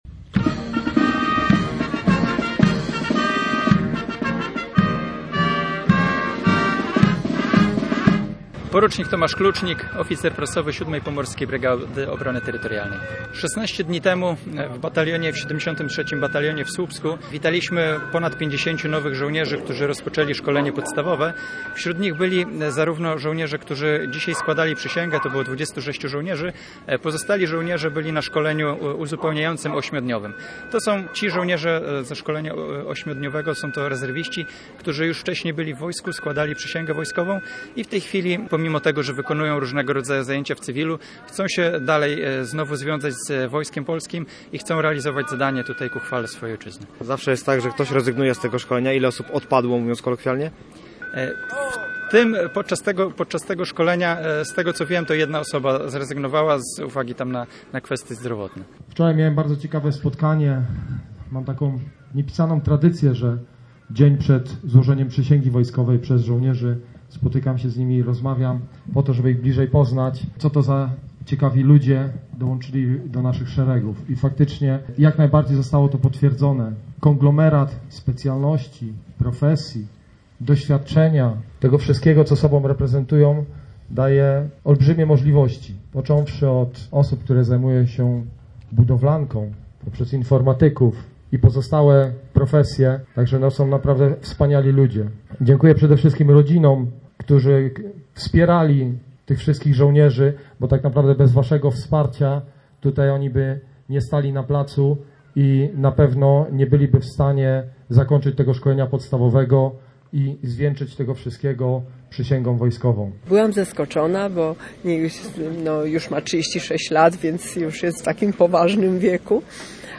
26 żołnierzy Wojsk Obrony Terytorialnej złożyło dziś przysięgę w porcie w Ustce. To mundurowi, którzy przeszli podstawowe szesnastodniowe szkolenie.